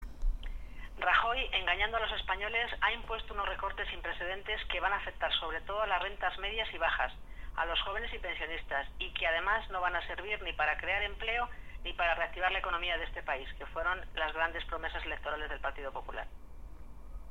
“Sería una actitud coherente por parte del PP”, añade la senadora socialista Inmaculada Cruz, que anima al PP de Cuenca a llevar a cabo esta nueva campaña contra el máximo responsable de su partido, Mariano Rajoy, “protegiendo así, como predican, el bolsillo de los conquenses”.
Cortes de audio de la rueda de prensa